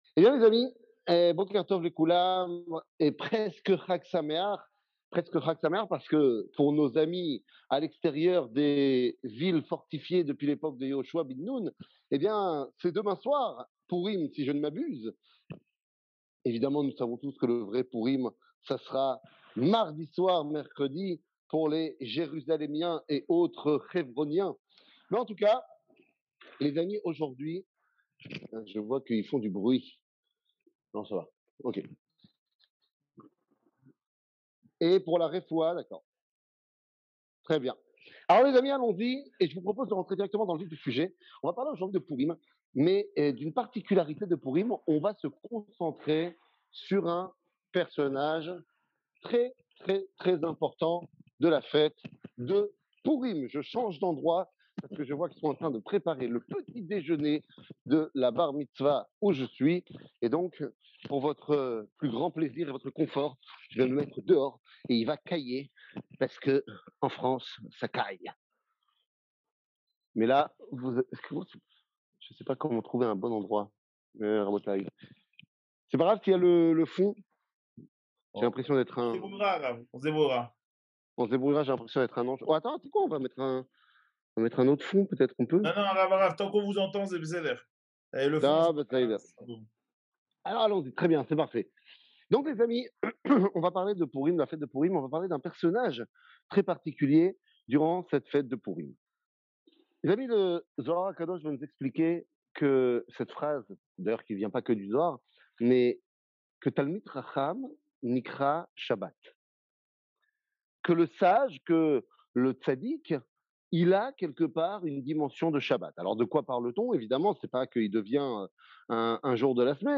קטגוריה La fête de Pourim 00:53:29 La fête de Pourim שיעור מ 05 מרץ 2023 53MIN הורדה בקובץ אודיו MP3